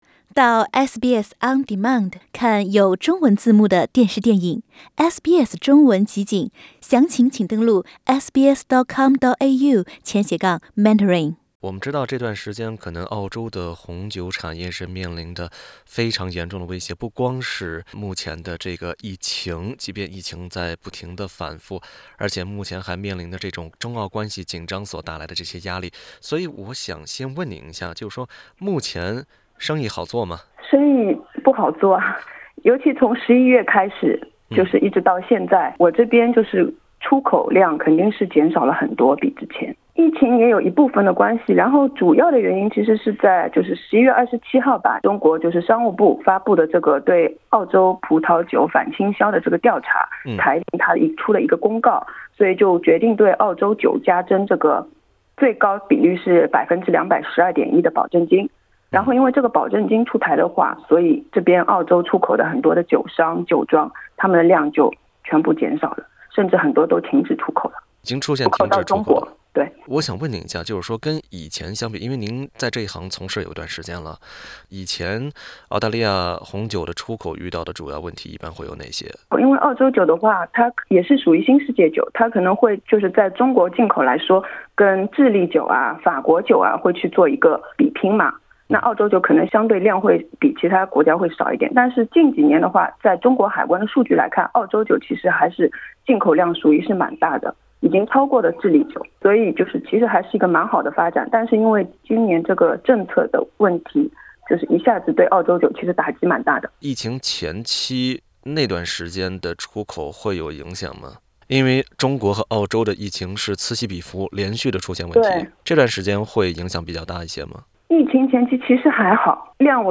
澳大利亚的葡萄酒酒商和物流在中国出台反倾销调查之后开始了转型之路。但是，据业内人士表示，替代方案无法弥补葡萄酒出口受限所带来的损失。（欢迎点击图片音频，收听采访。）